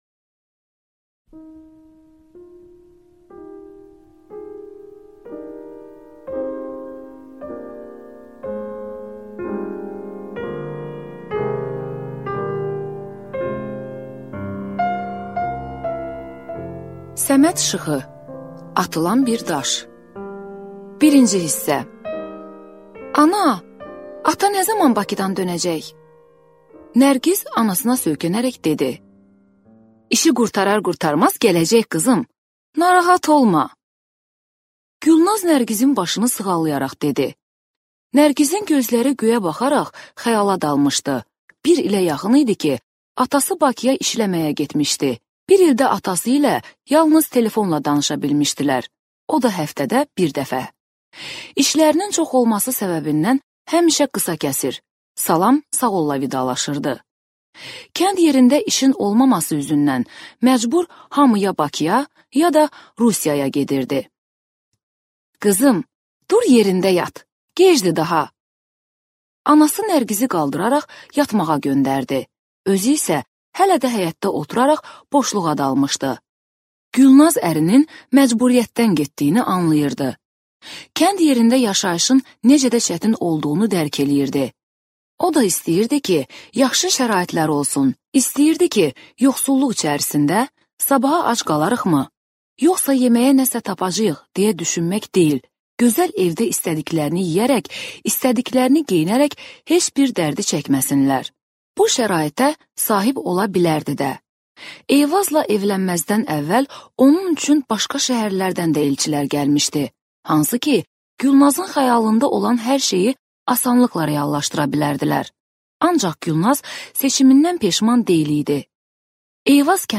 Аудиокнига Atılan bir daş | Библиотека аудиокниг